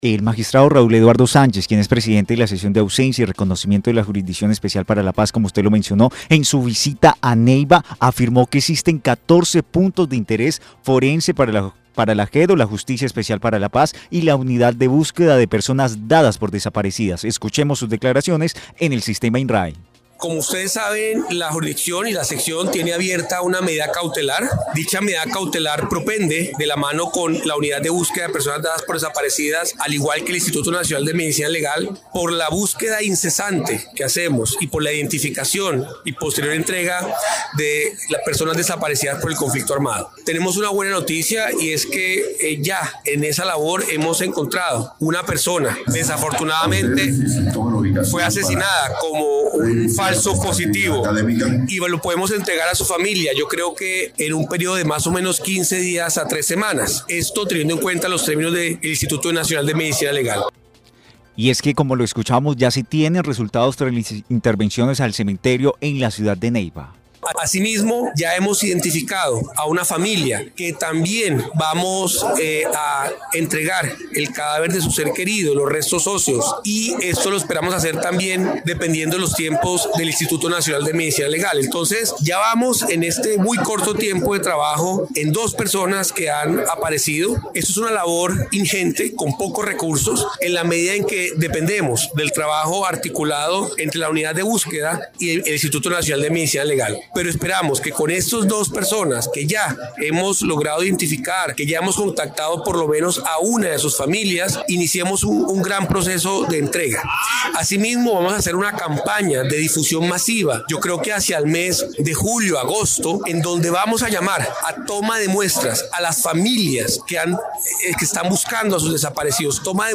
El Magistrado Raúl Eduardo Sánchez Presidente de la Sesión de Ausencia y Reconocimiento de la Jurisdicción Especial para la Paz indicó que ya hay resultados tras la intervención al cementerio de Neiva.
VOZ_MAGISTRADO.mp3